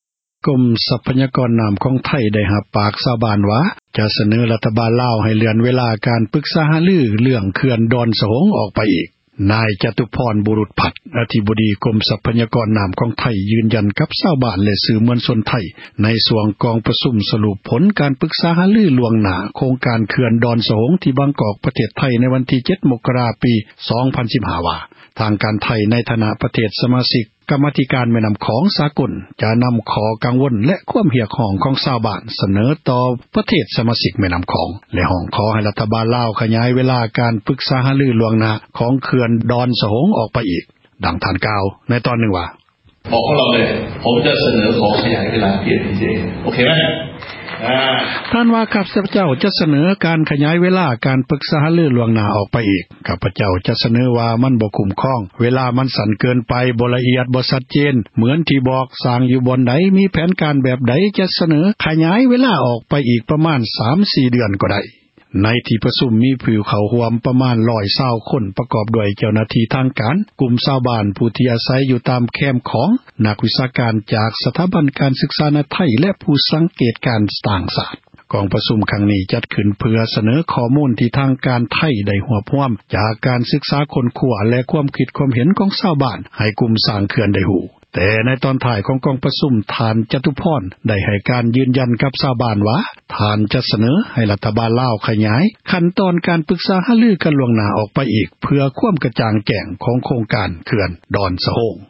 ນາຍ ຈະຕຸພອນ ບຸຣຸດພັດ ອະທິບໍດີ ກົມ ຊັພຍາກອນ ນ້ຳ ຂອງໄທ ຢືນຢັນ ກັບ ຊາວບ້ານ ແລະ ສື່ມວນຊົນ ໄທ ໃນຊ່ວງ ກອງປະຊຸມ ສລຸບ ຜົລ ການປຶກສາ ຫາລື ລ່ວງຫນ້າ ໂຄງການ ເຂື່ອນ ດອນສະໂຮງ ທີ່ ບາງກອກ ປະເທດໄທ ເມື່ອວັນທີ 7 ມົກກະຣາ 2015 ວ່າ ທາງການໄທ ໃນຖານະ ປະເທດ ສະມາຊິກ ກັມມາທິການ ແມ່ນ້ຳຂອງ ສາກົນ ຈະນຳຂໍ້ ກັງວົນ ແລະ ຄວາມ ຮຽກຮ້ອງ ຂອງ ຊາວບ້ານ ສເນີຕໍ່ ປະເທດ ສະມາຊິກ ແມ່ນ້ຳຂອງ ແລະ ຮ້ອງຂໍ ໃຫ້ ຣັຖບານ ລາວ ຍືດ ເວລາ ການປຶກສາ ຫາລື ລ່ວງຫນ້າ ຂອງ ເຂື່ອນ ດອນ ສະໂຮງ ອອກໄປ ອີກ. ດັ່ງທ່ານ ກ່າວວ່າ: